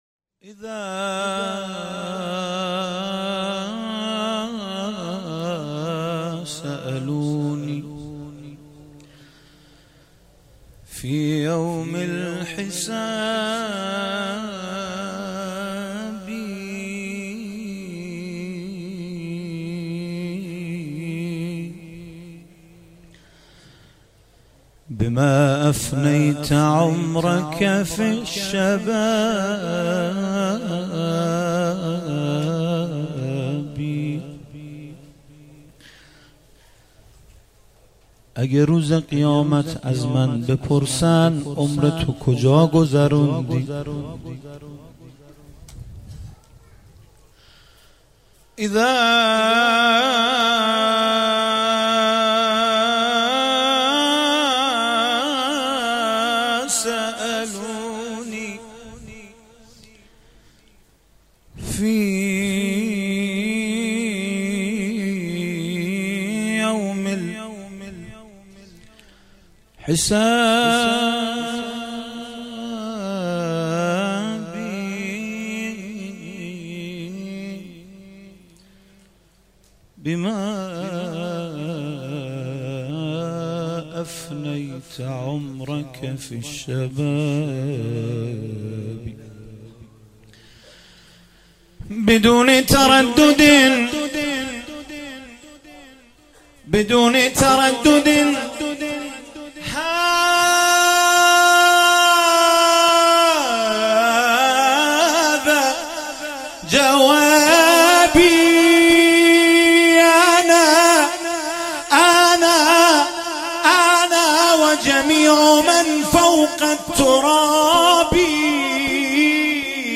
دانلود مولودی معروف عربی برای میلاد امام رضا